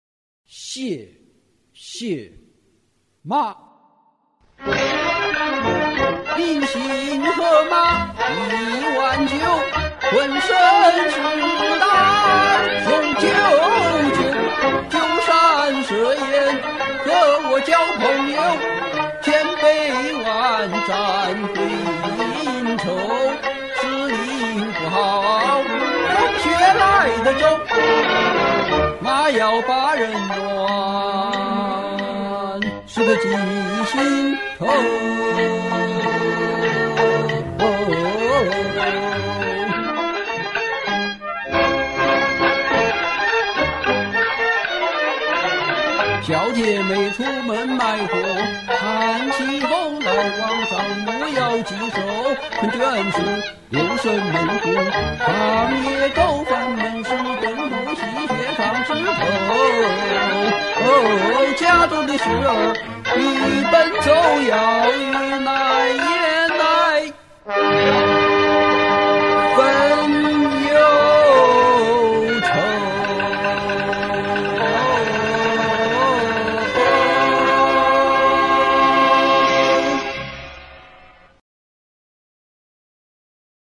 革命现代京剧
【西皮二六】